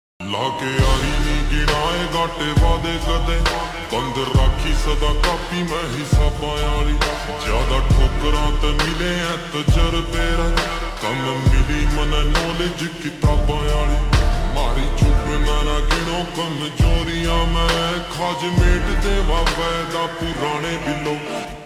Haryanvi Song
(Slowed + Reverb)